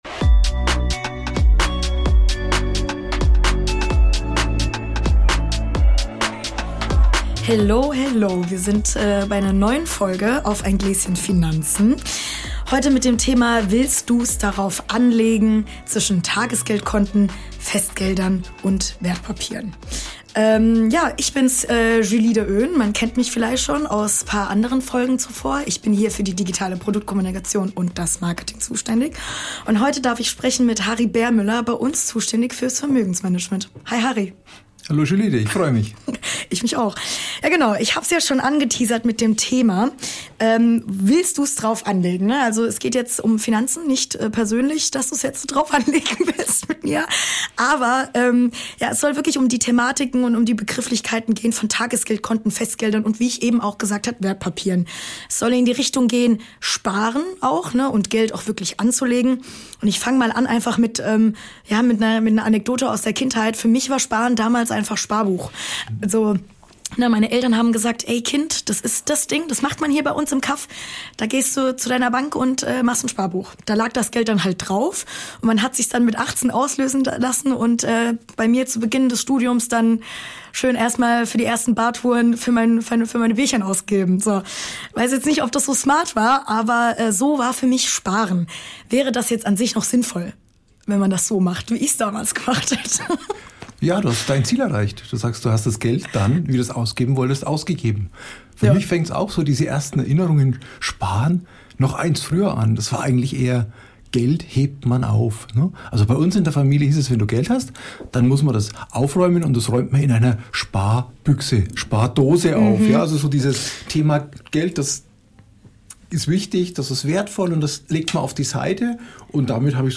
Ehrlich, humorvoll und natürlich mit einer Portion Finanzwissen.